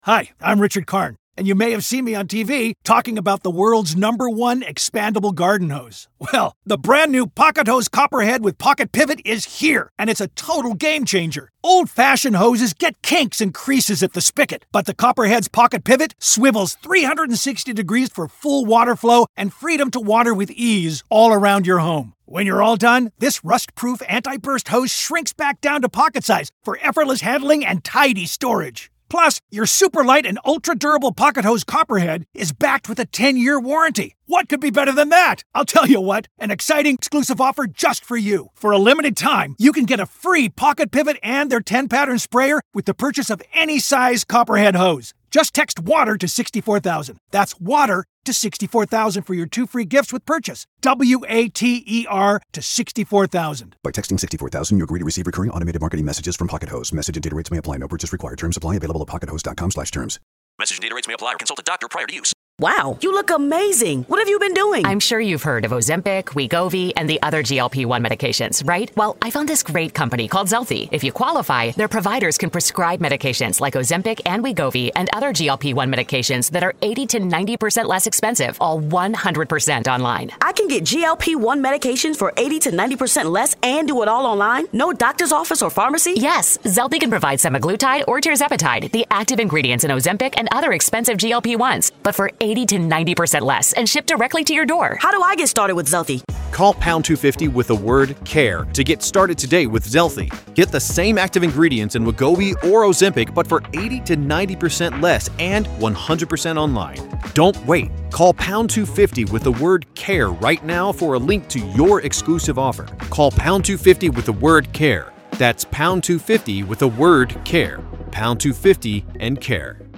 This exclusive interview reveals the prosecutorial tactics that have created an almost insurmountable credibility gap between the two sides.